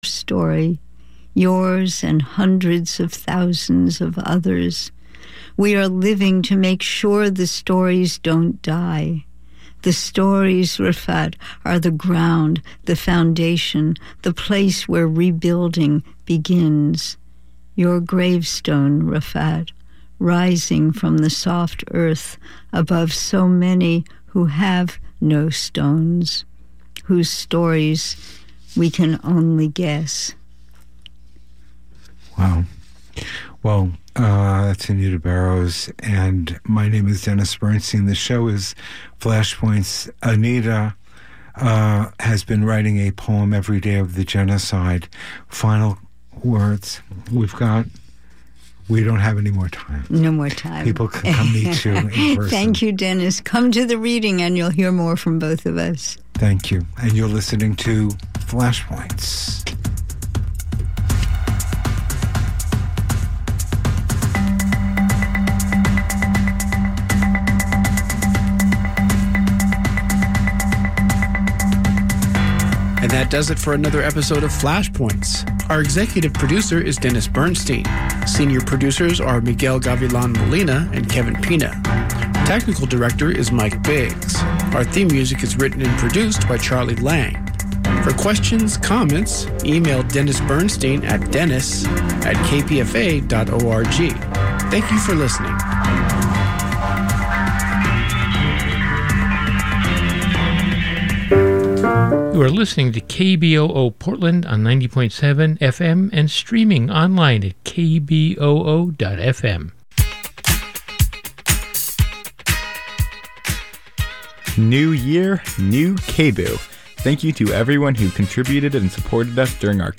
America's First (and Best) Radio Show About Board Games